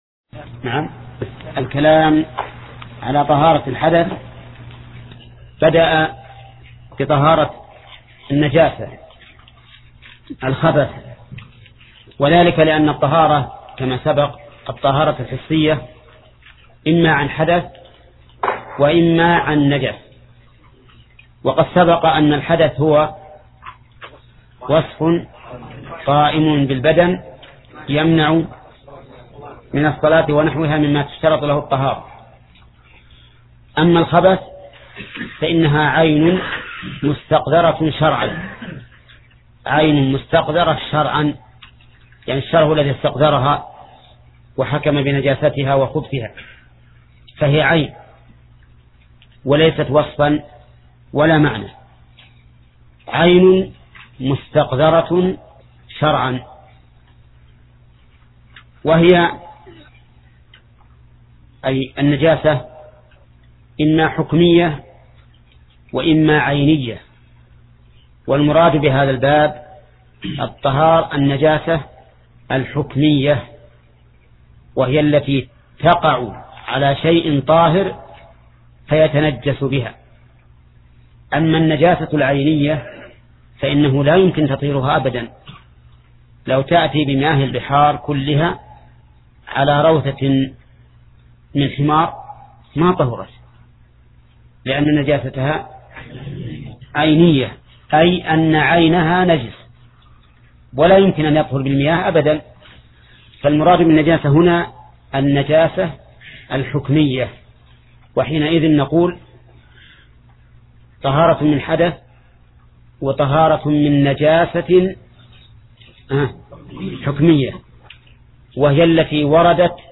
درس (21): باب إزالة النجاسة